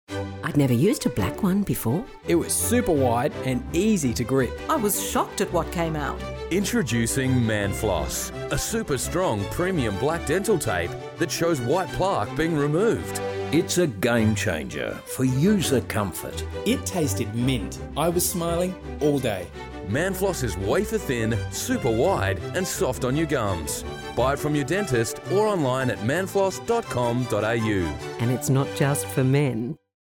Manfloss Radio Ad